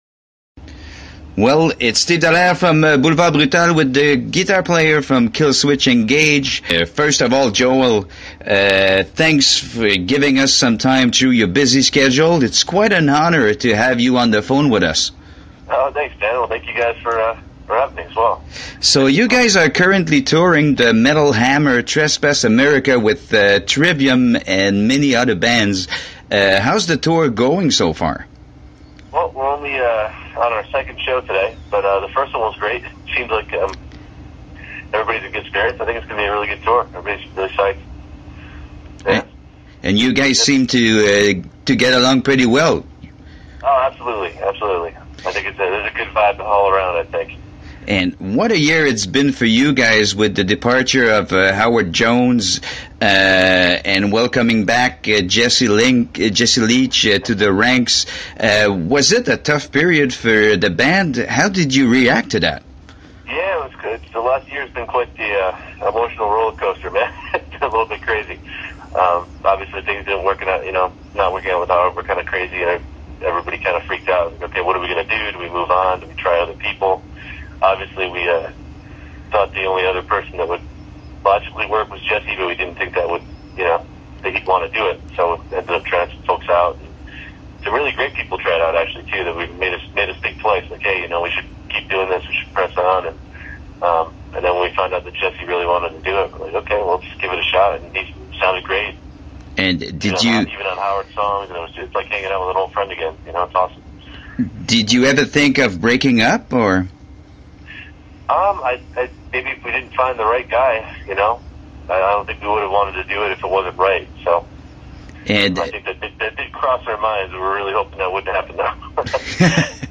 J’ai eu la chance de m’entretenir avec le guitariste Joel Stroetzel de la formation Killswitch Engage dimanche dernier et il nous livre des commentaires intéressants sur l’avenir du groupe, Heavy MTL, son pire show à vie et bien plus.
killswitch-engage-entrevue.mp3